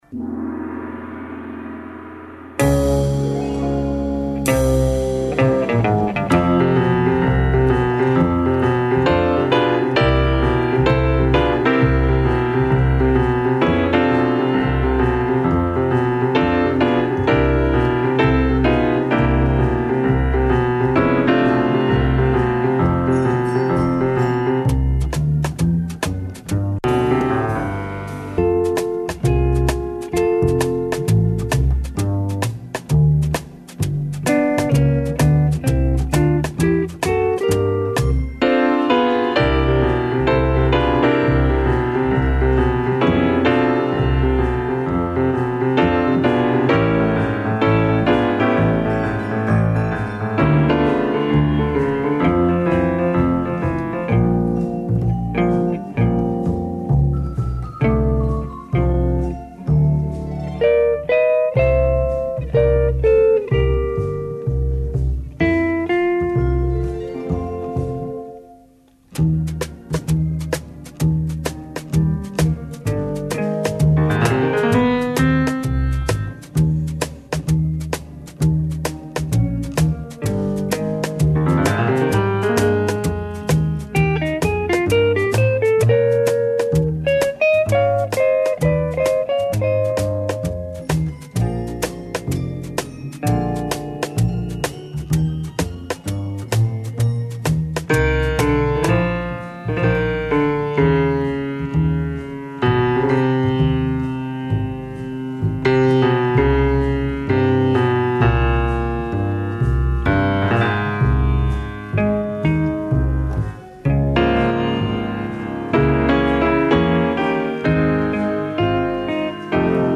Емисија се емитује из Ариља где се по четврти пут одржава АРЛЕММ. Разговараћемо с тинејџерима који, више од других музичких жанрова, воле и слушају класичну музику.